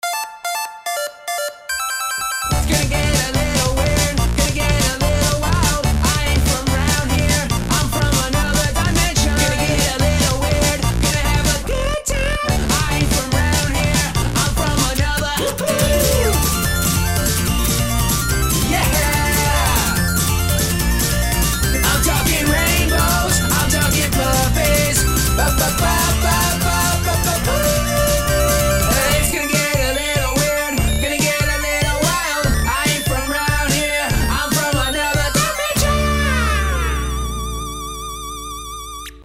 • Качество: 320, Stereo
громкие
веселые
саундтреки
Electronic
Pop Rock
быстрые